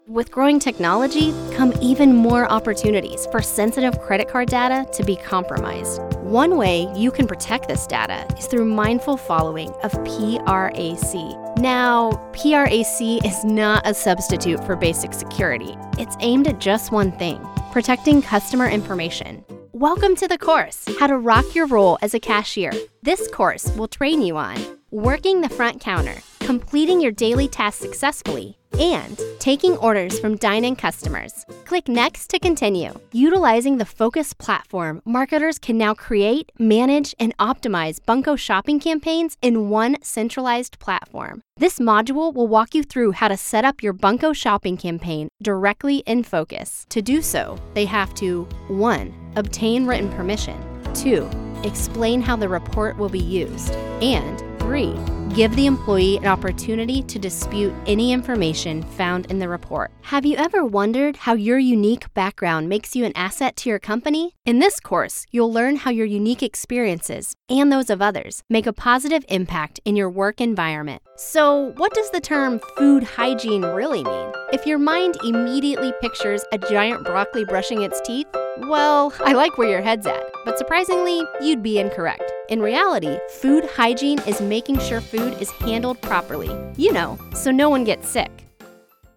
Female
Yng Adult (18-29), Adult (30-50)
I can bring a professional tone to an e-learning course.
E-Learning
Versatile, Professional.